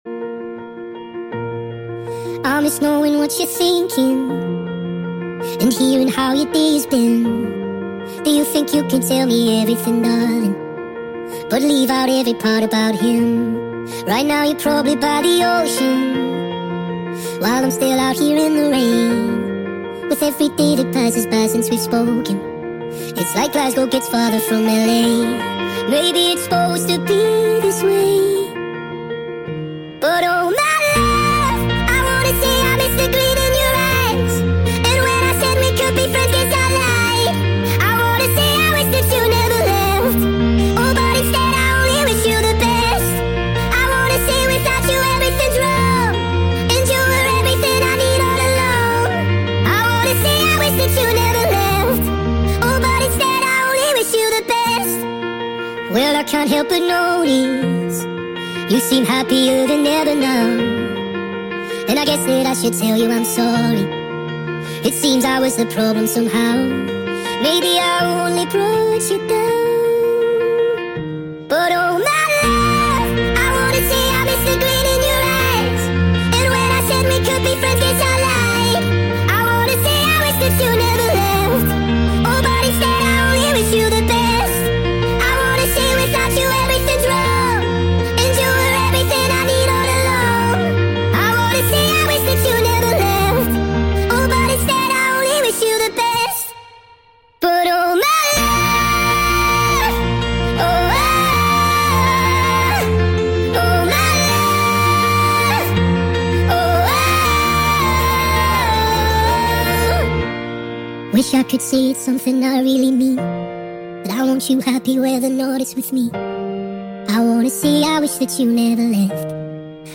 sped up (1.5x)